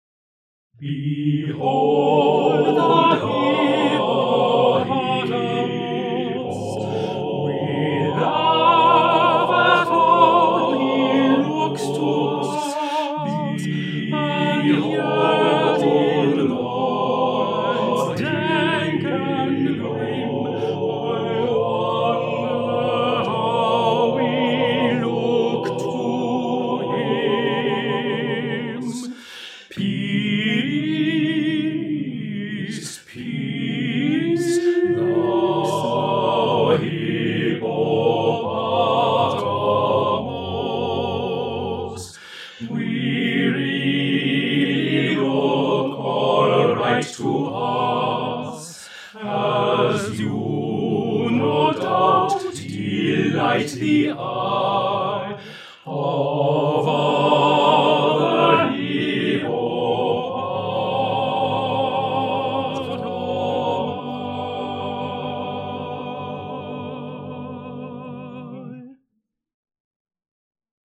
TTBB Chorus a cappella